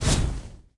Media:BarbarianElite_flame_atk.wav 攻击音效 atk 精锐形态狂暴攻击音效
BarbarianElite_flame_atk.wav